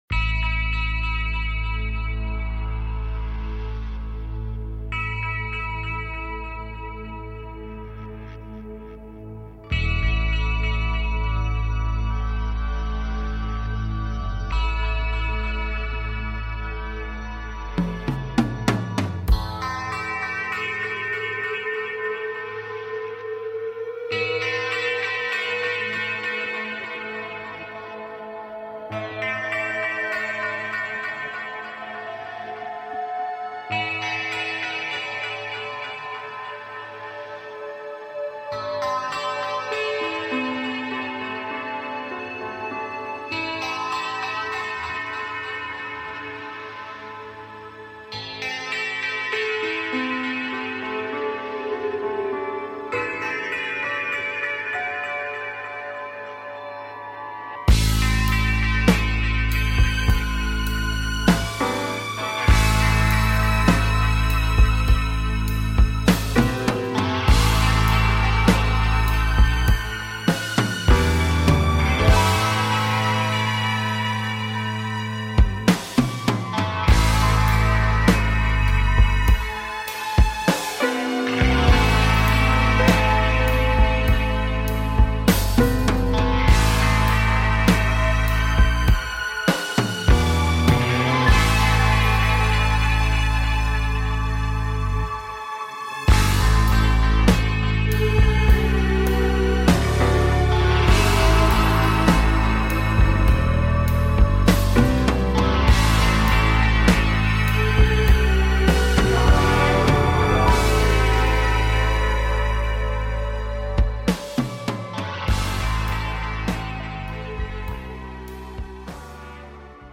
Talk Show Episode
Reviewing listener projects and answering listener calls